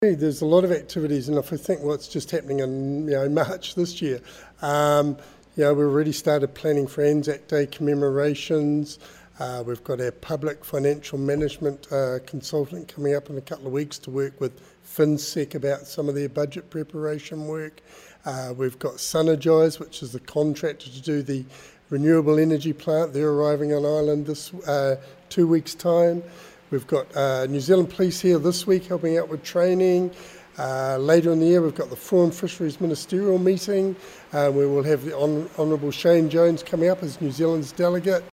NZ High Commissioner to Niue-HE.Mark Gibb